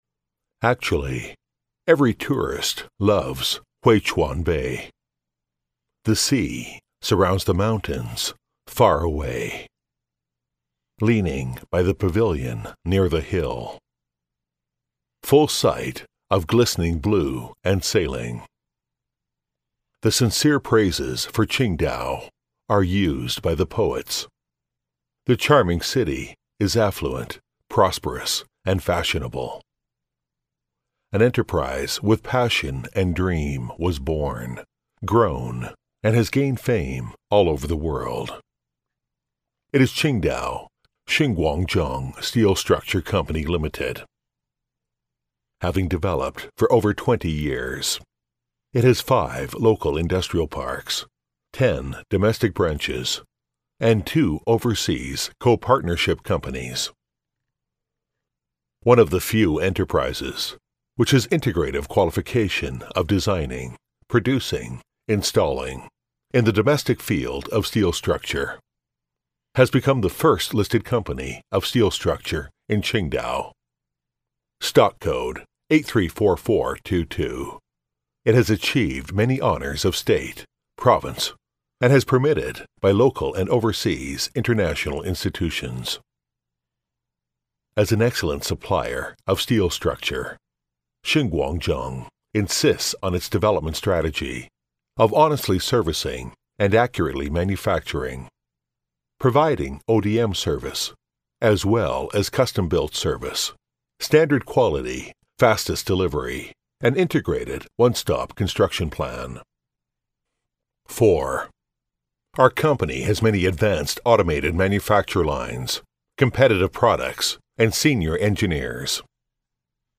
英语配音美式英语配音
• T110-1 美式英语 男声 自述风格 大气浑厚磁性|沉稳|低沉|娓娓道来|素人